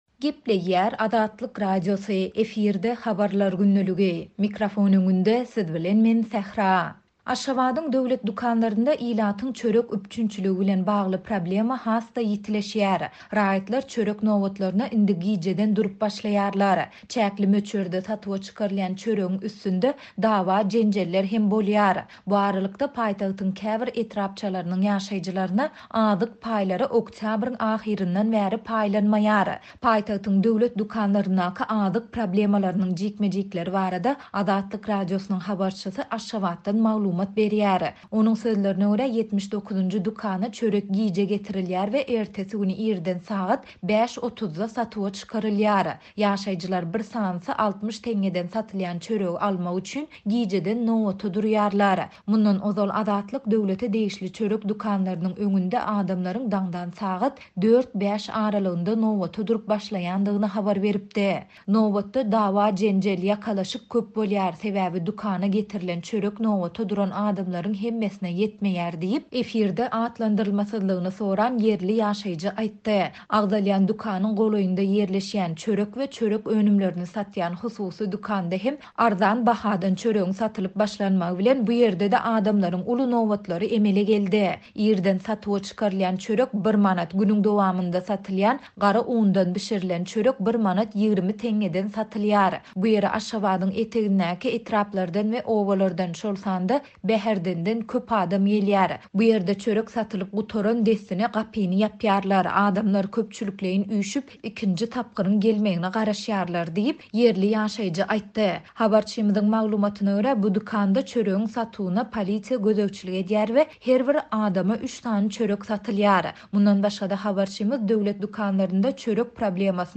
Paýtagtyň döwlet dükanlaryndaky azyk problemalarynyň jikme-jikleri barada Azatlyk Radiosynyň habarçysy Aşgabatdan maglumat berýär.